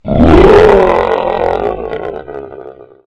WR_TypeF_Die.wav